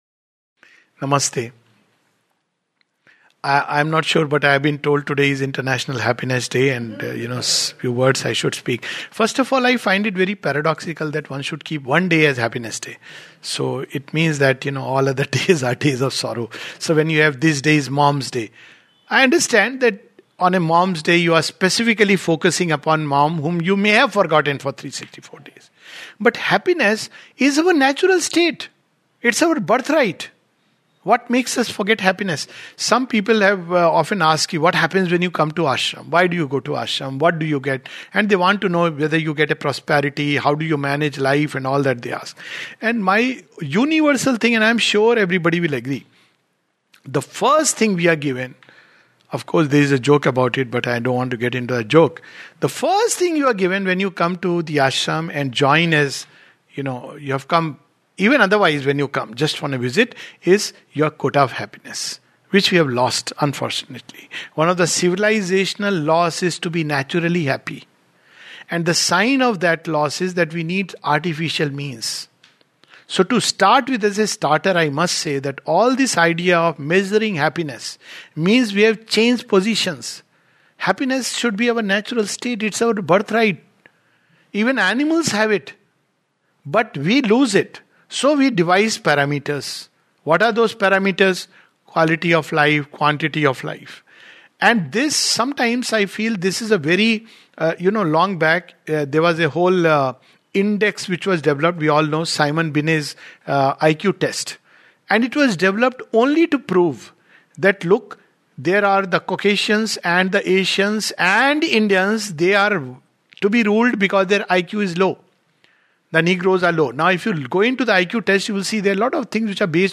This brief talk is a little comment on International Happiness Day.